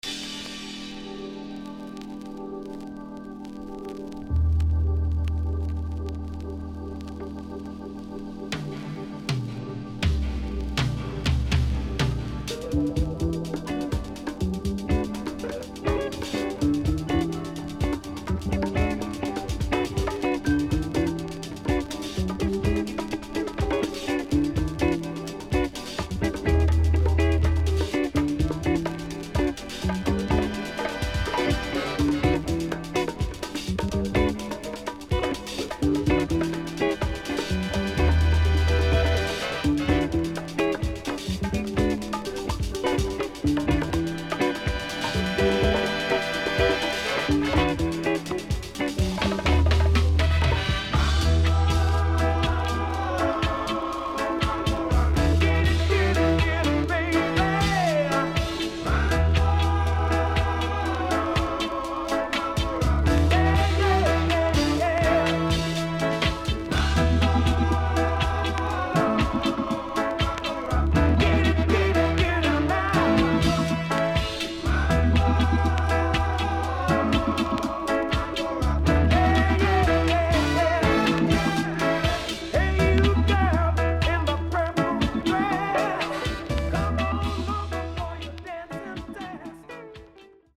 HOME > LP [VINTAGE]  >  FUNKY REGGAE
SIDE A:所々チリノイズがあり、少しプチノイズ入ります。